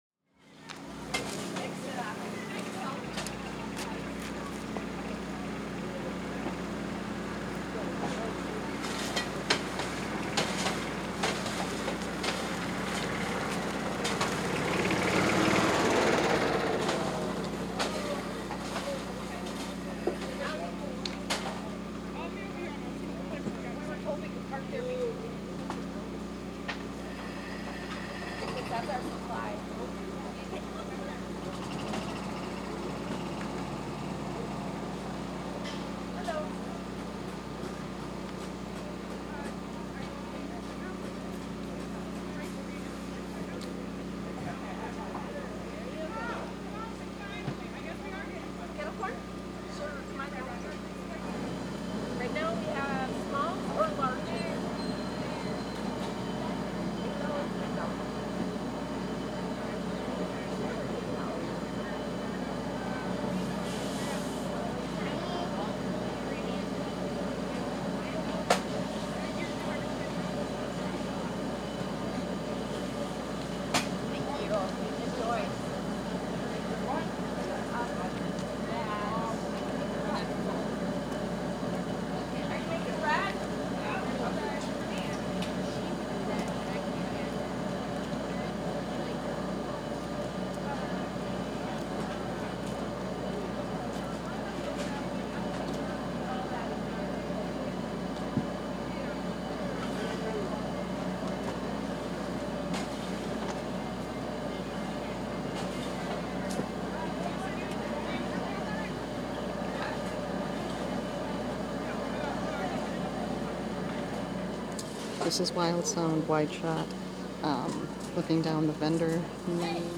Menominee PowWow 4 Aug 2023 Vendor Lane.wav